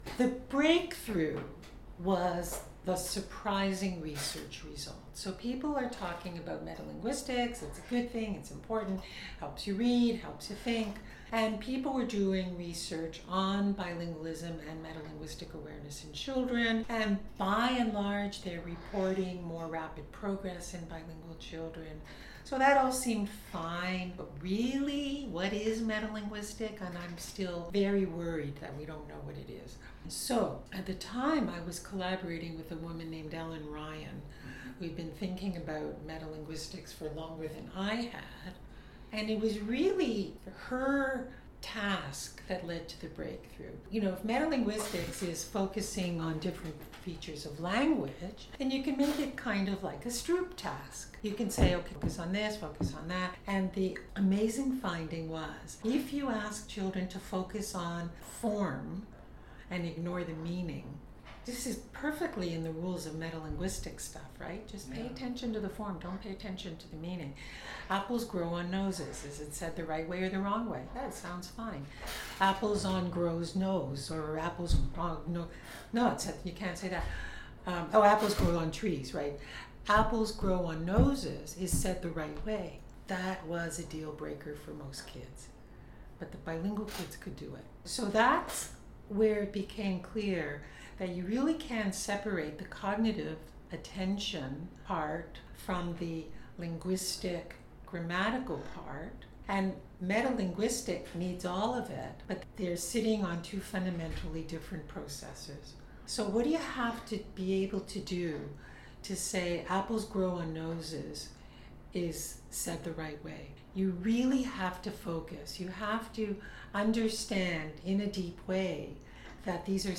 In meeting with me she prefaced the conversation (and the recording I took) with the following consideration: her vocal tract was degraded by a health issue, and is acoustically different than what she grew up with.